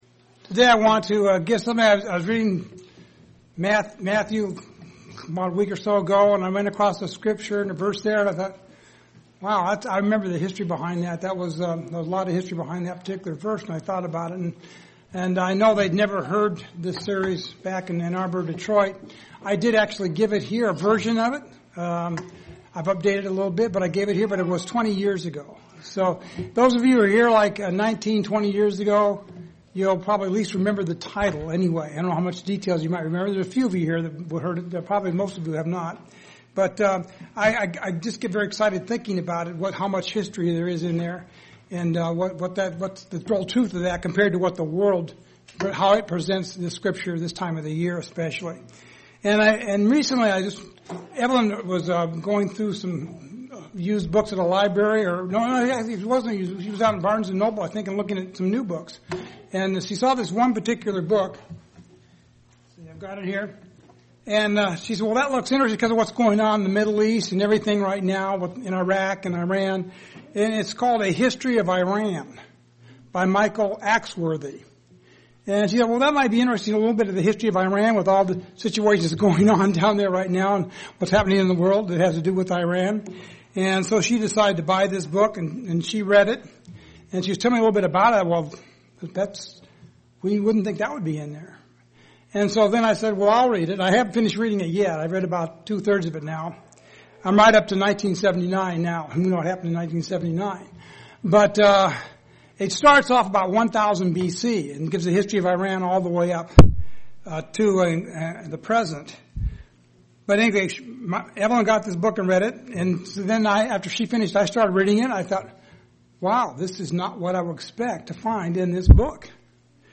This story begs for clarification of the facts. sermon Transcript This transcript was generated by AI and may contain errors.